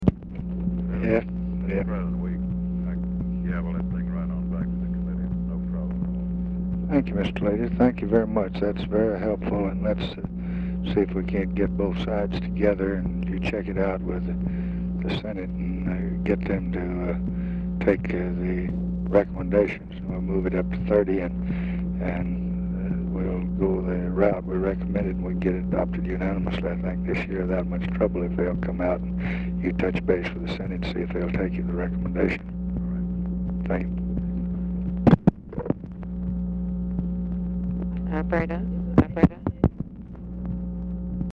Telephone conversation # 10759, sound recording, LBJ and HALE BOGGS, 9/13/1966, 12:30PM | Discover LBJ
POOR SOUND QUALITY
Format Dictation belt
Specific Item Type Telephone conversation Subject Congressional Relations Labor Legislation